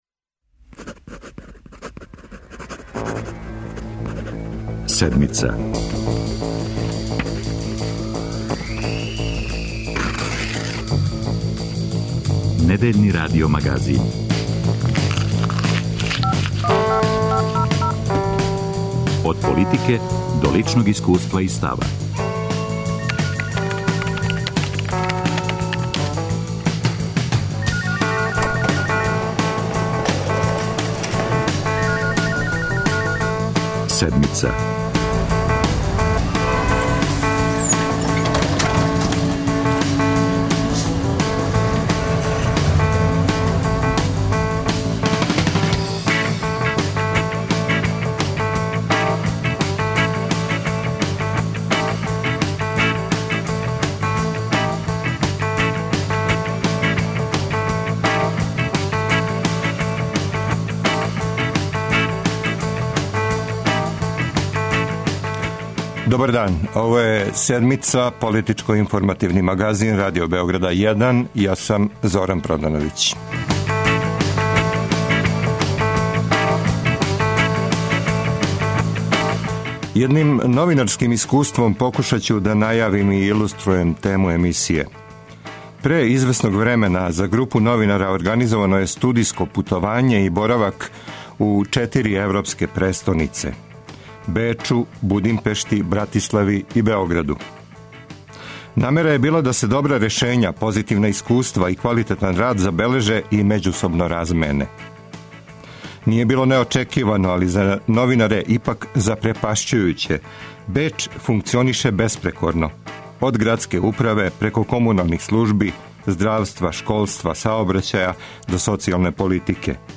Гости емисије - шефови посланичких група Српске напредне странке и Демократске странке Србије у градском парламенту Александар Јовичић и Андреја Младеновић, као и председник градског одбора Демократске странке у Скупштини Београда Балша Божовић.